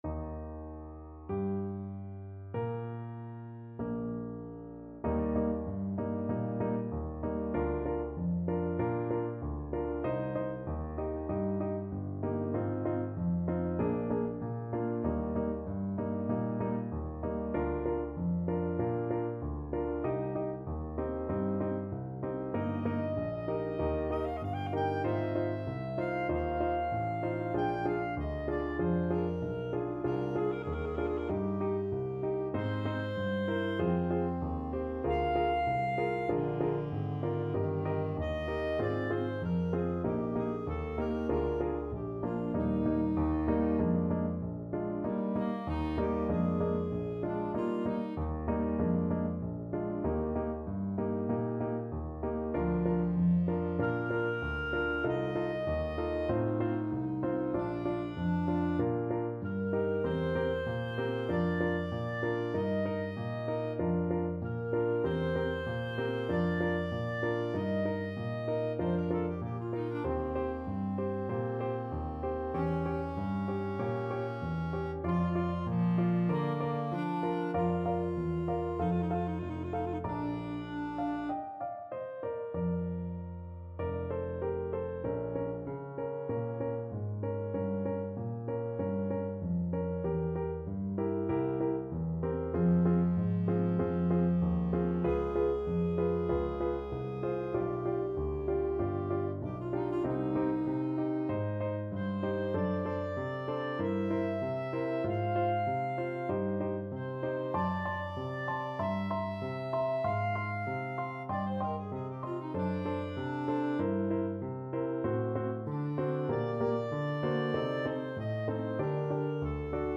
Adagio =48
4/4 (View more 4/4 Music)
Classical (View more Classical Clarinet Duet Music)
Relaxing Music for Clarinet